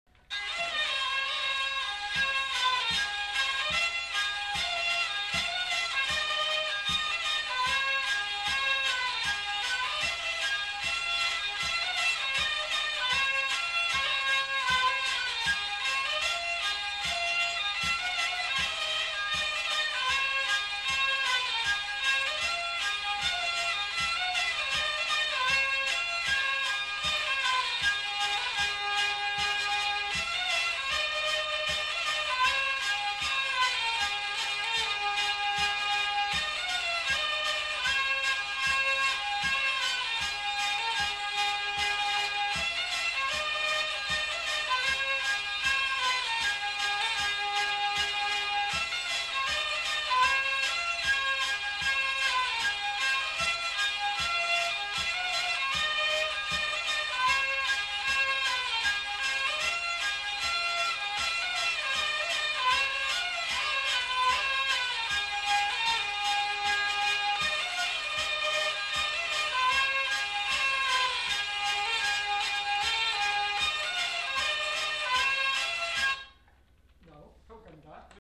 Lieu : Vielle-Soubiran
Genre : morceau instrumental
Instrument de musique : vielle à roue
Danse : scottish